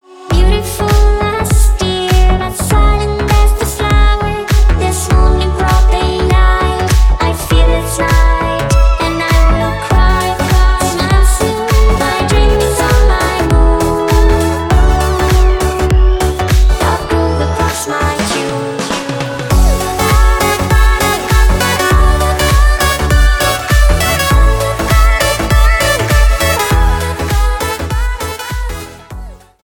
• Качество: 320 kbps, Stereo
Поп Музыка
клубные